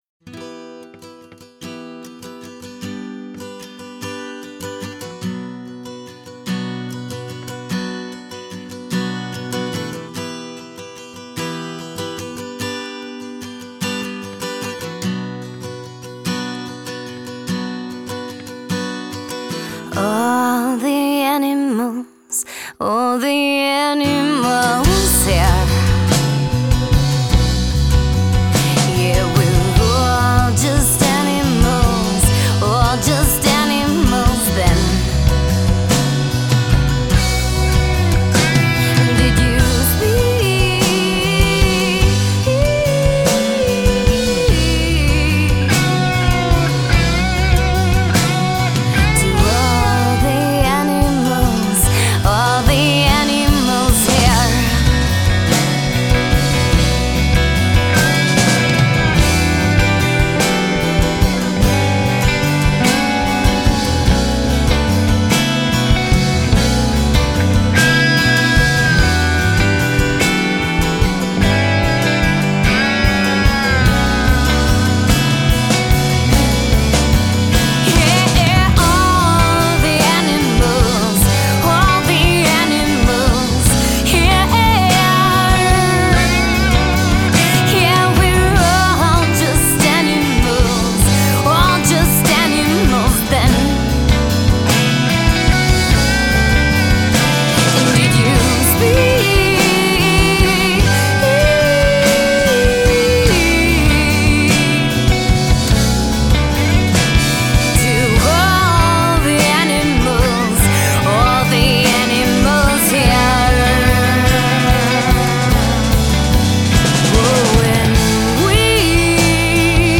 folk-alt-indie-rock band
Music for travelling folk.